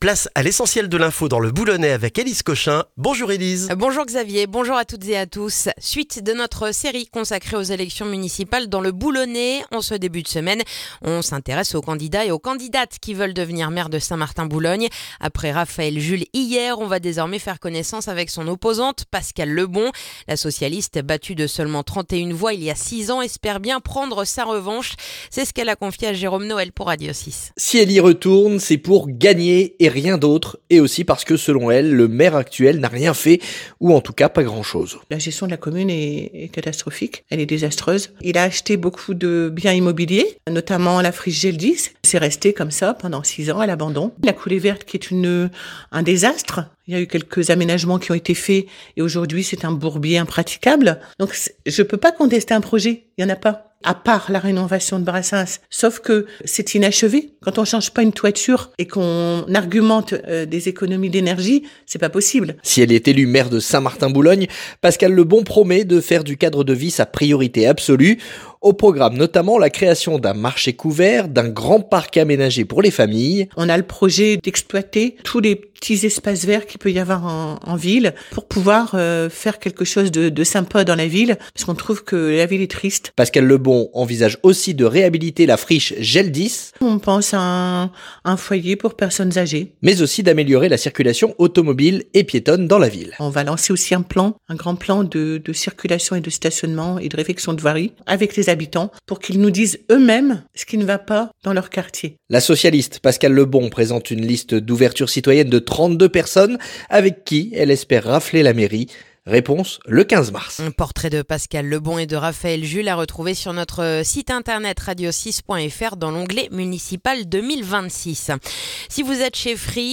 Le journal du mardi 3 mars dans le boulonnais